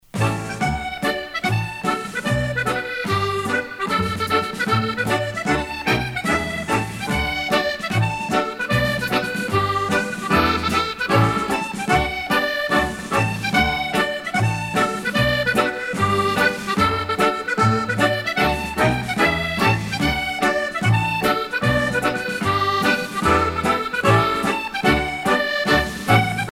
danse : marche